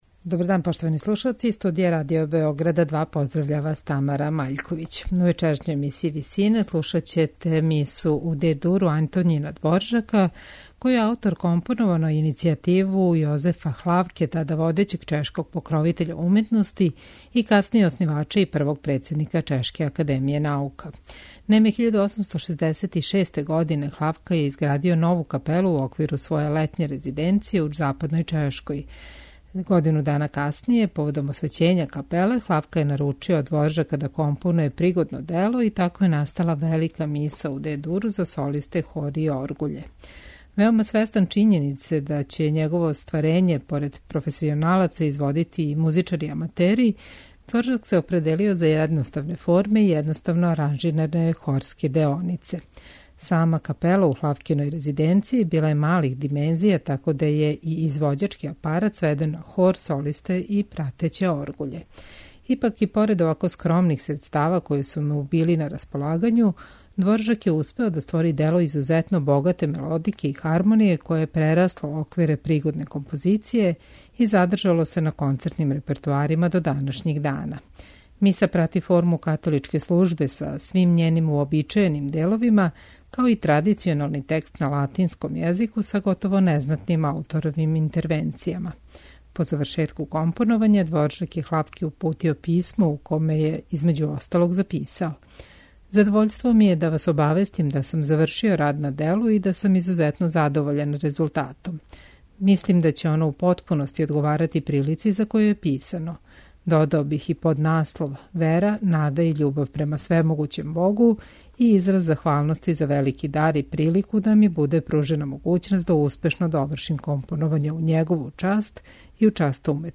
Годину дана касније, поводом освећења капеле, Хлавка је наручио од Дворжака да компонује пригодно дело и тако је настала велика Миса у Д-дуру за солисте, хор и оргуље.